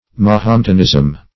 Mahometanism \Ma*hom"et*an*ism\, prop. n.
mahometanism.mp3